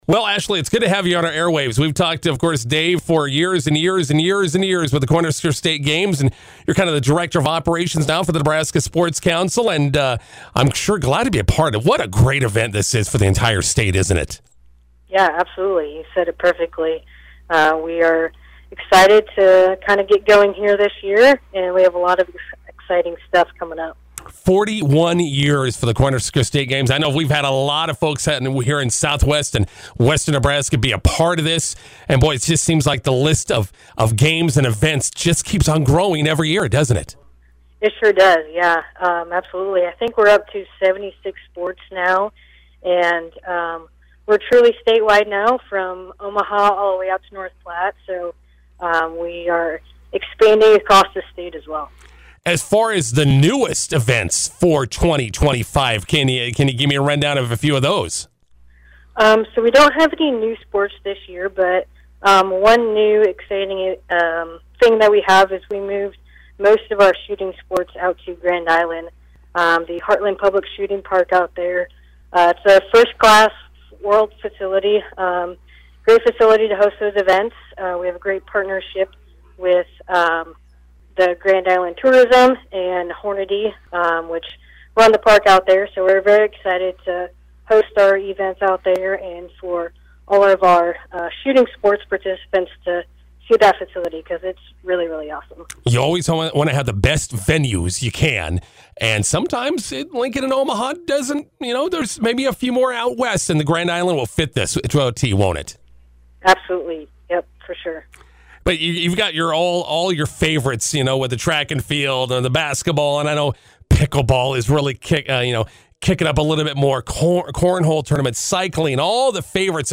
INTERVIEW: 2025 Cornhusker State Games kicking off next week.